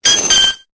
Cri de Mélodelfe dans Pokémon Épée et Bouclier.